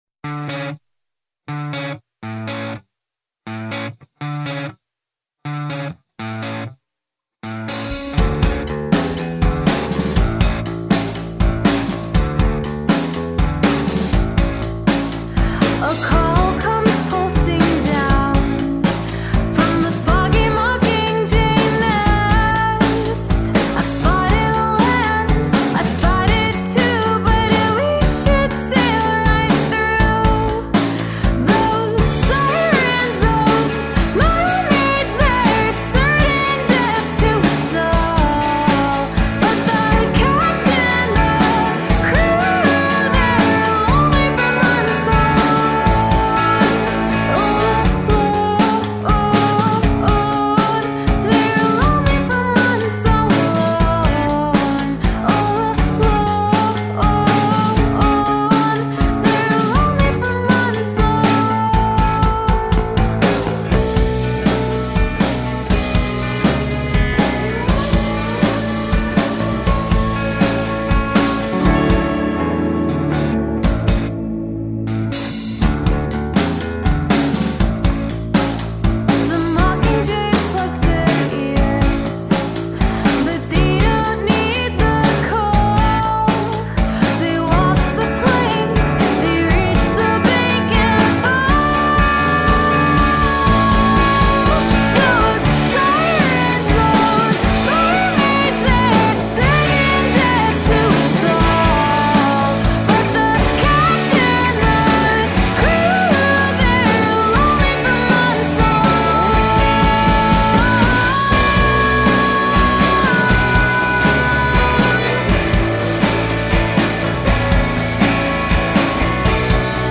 indie/rock/jazz trio